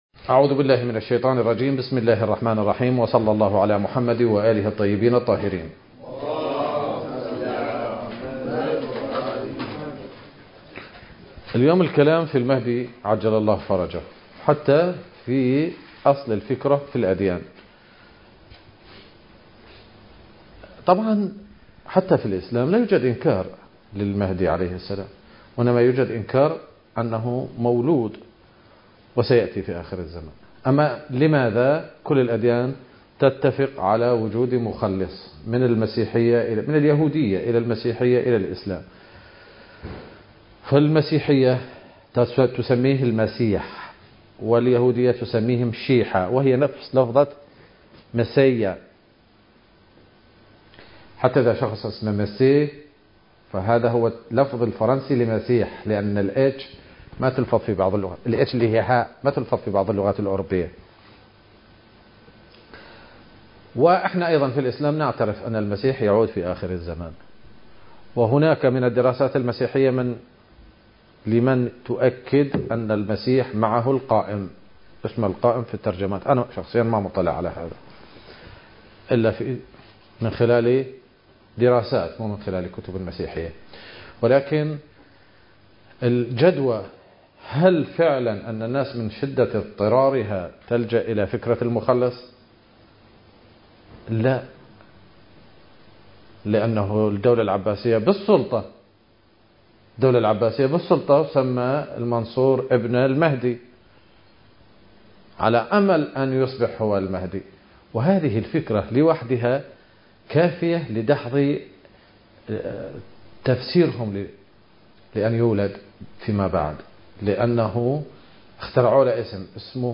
المكان: مضيف الإمام الحسن المجتبى (عليه السلام) / البصرة - سفوان التاريخ: 2023